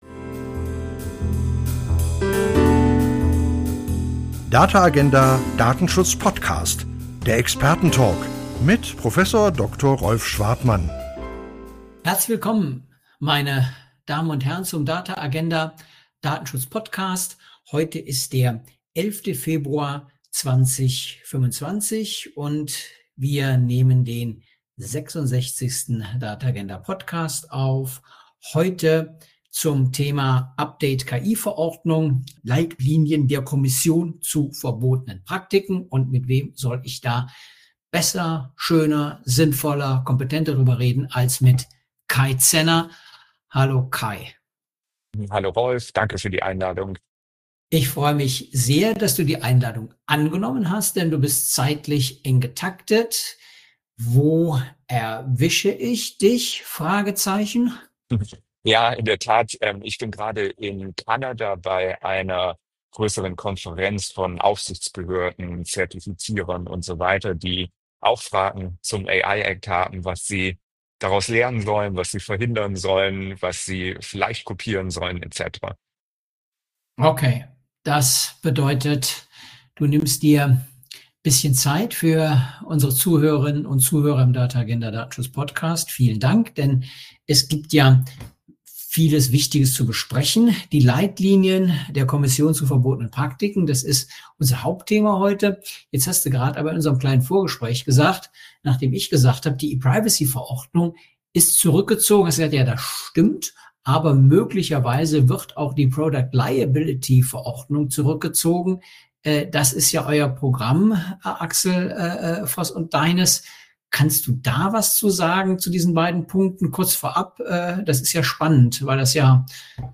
Der Datenschutz-Talk